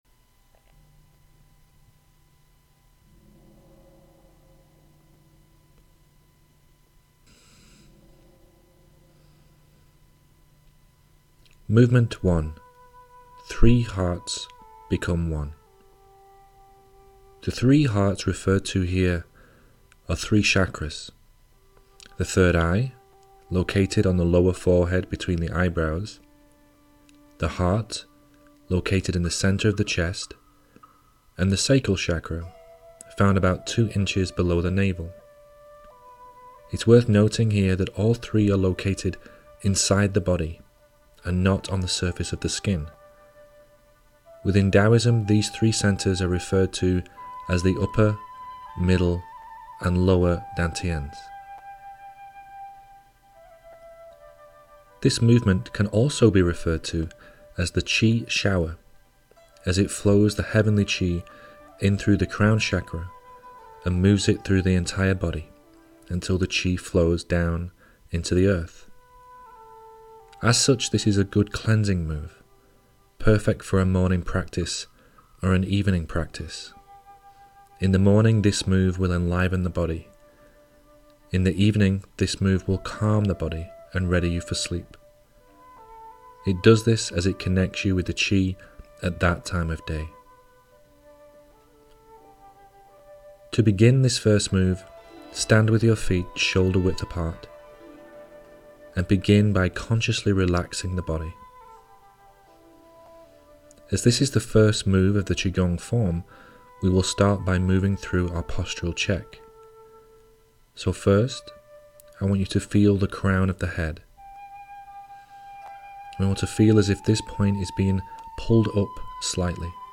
Audio Practice Session